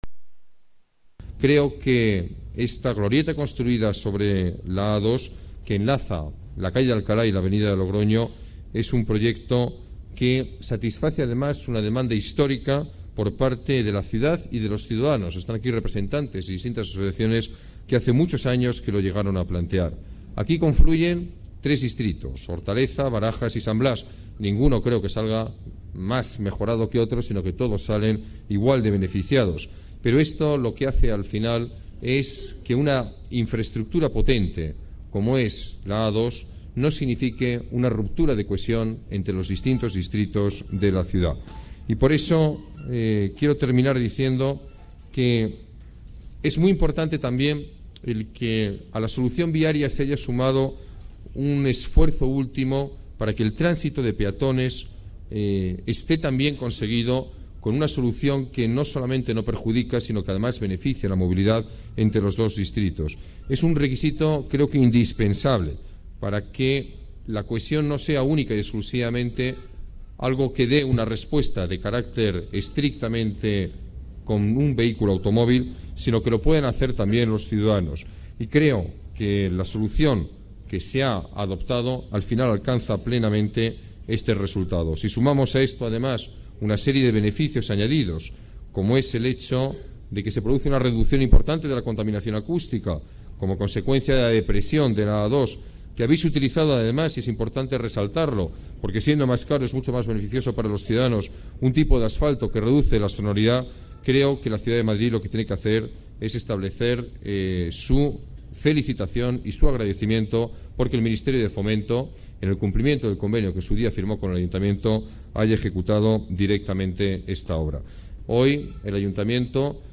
Nueva ventana:Declaraciones del alcalde, Alberto Ruiz-Gallardón: La nueva avenida de Logroño mejora la movilidad, ruido y elimina fronteras urbanas